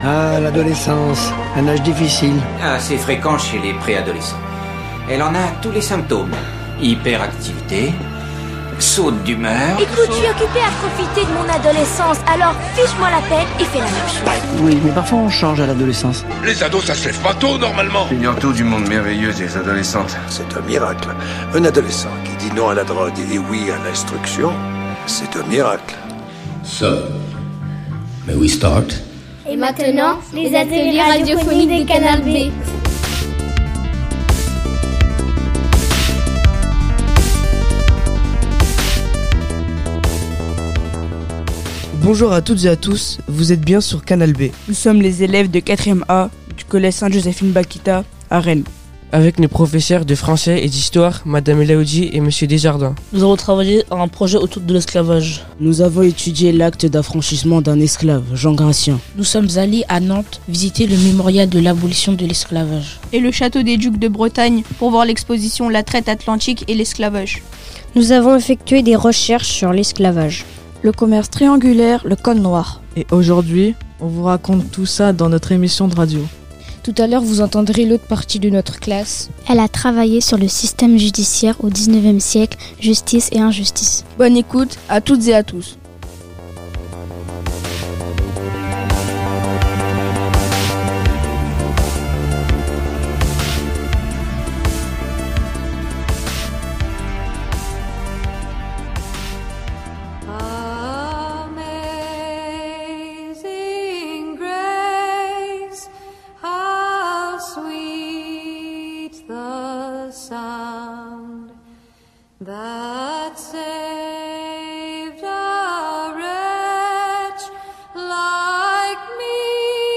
Il n'y en a pas toutes les semaines, mais c'est sur ce créneau que nous vous proposons d'écouter les productions réalisées durant les ateliers d'éducation aux média radio mis en place par Canal B.
Depuis plus de quatre ans, Canal B travaille très régulièrement avec l’association Langue et Communication et vous avez pu entendre ici des émissions réalisées avec des personnes migrantes venues d’Afghanistan, de Syrie, d’Iran, d’Irak, de Georgie, d’Ukraine, du Mexique, d’Argentine, du Soudan, d’Erythrée, du Maroc, de Mongolie, de Thaïlande et d’autres pays encore